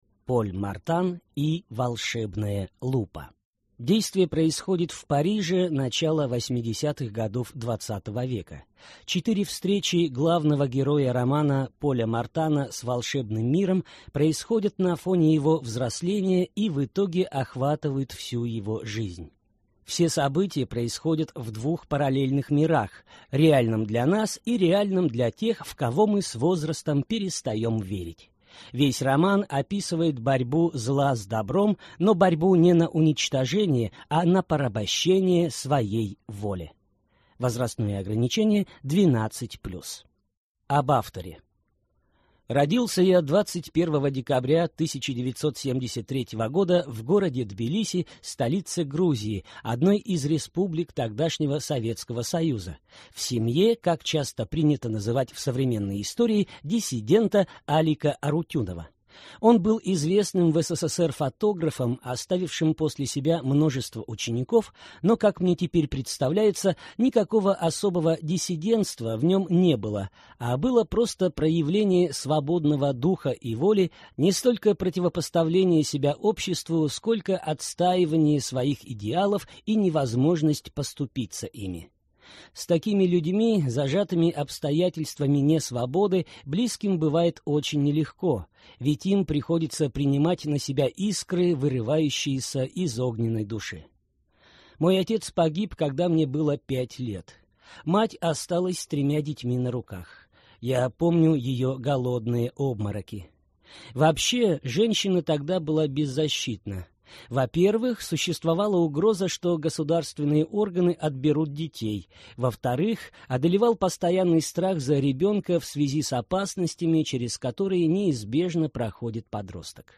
Аудиокнига Поль Мартан и волшебная лупа | Библиотека аудиокниг